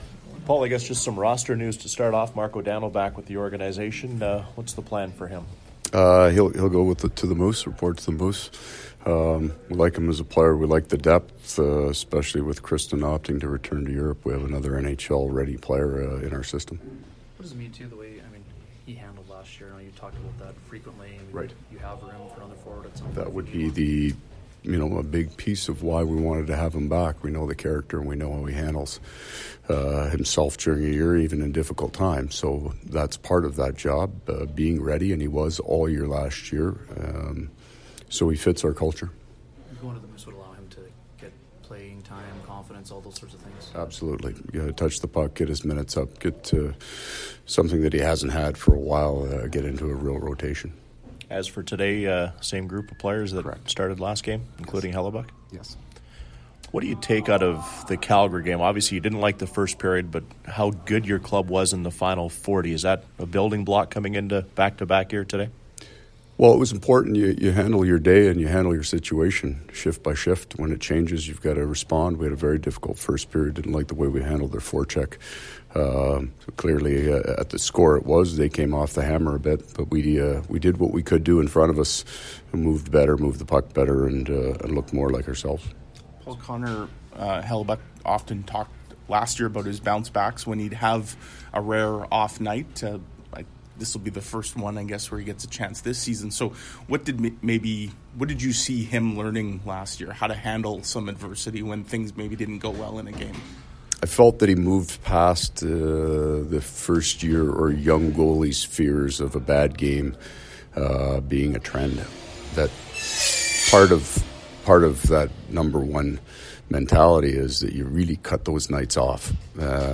A look at the game 21 projected lineup along with pre-game audio.